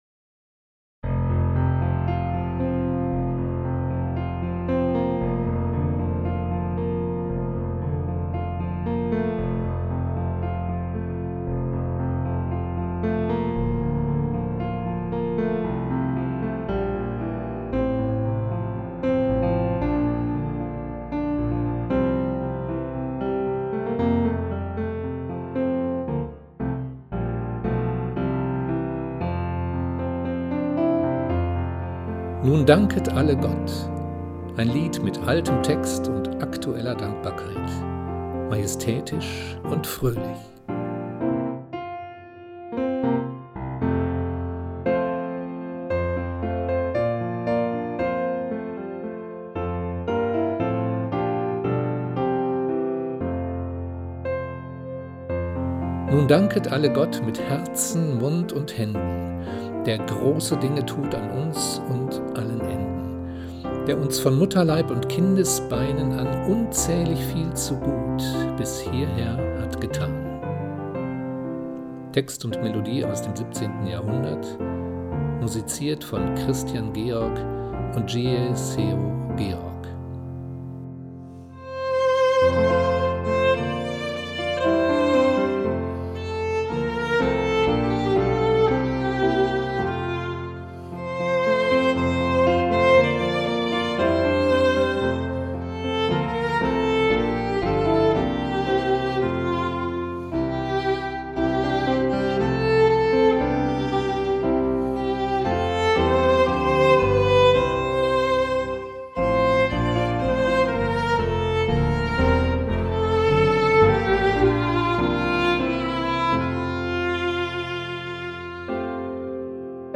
Piano & Arrangement
Geige
Nun danket alle Gott - Musik und Impuls.mp3